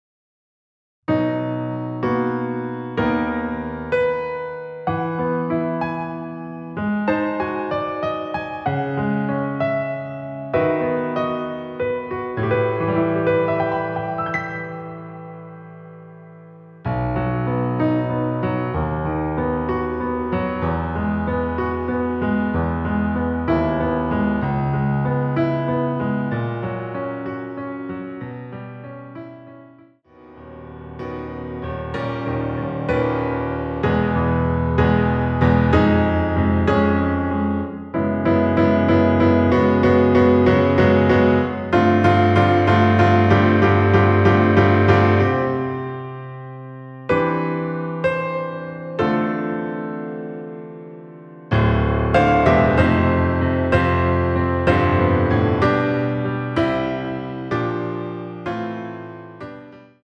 피아노 버전 MR입니다.
반주가 피아노 하나만으로 되어 있습니다.(아래의 유튜브 동영상 참조)
앞부분30초, 뒷부분30초씩 편집해서 올려 드리고 있습니다.
중간에 음이 끈어지고 다시 나오는 이유는